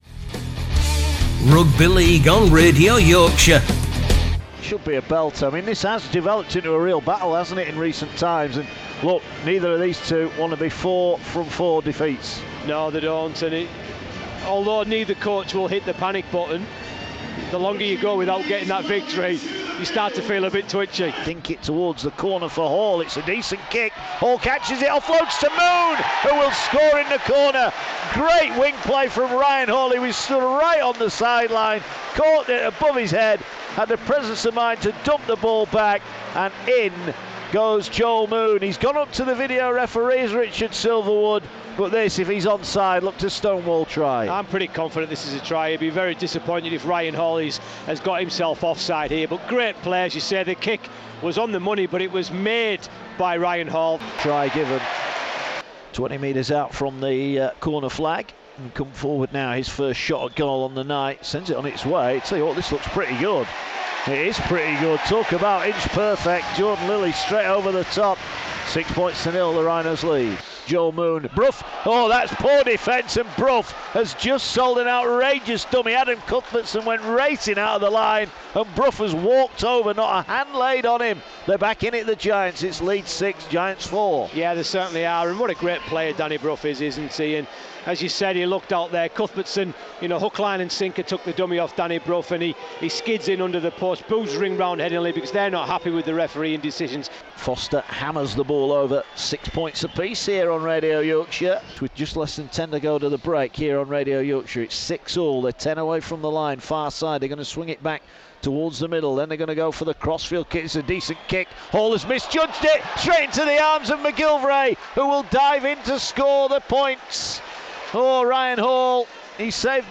Highlights of Leeds Rhinos first win in the Super League 2016 season, Huddersfield now no wins in four games.